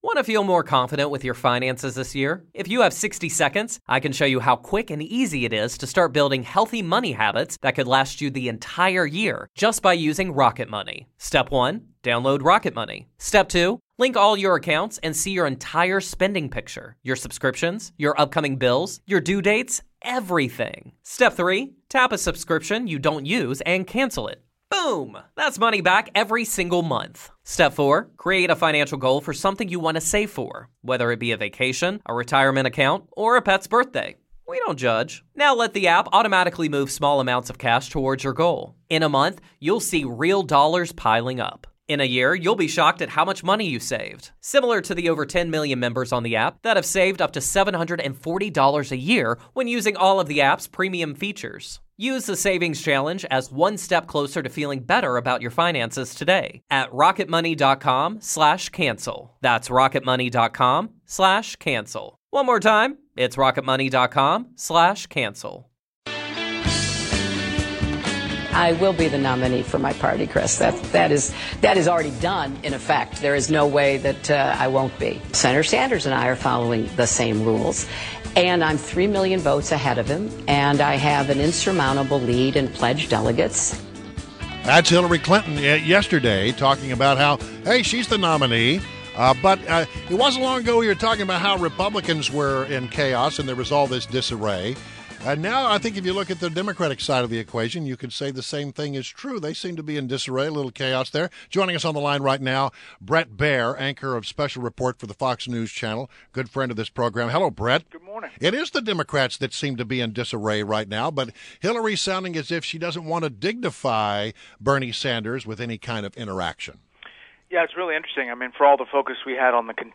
INTERVIEW — BRET BAIER – Anchor, Special Report, Fox News Channel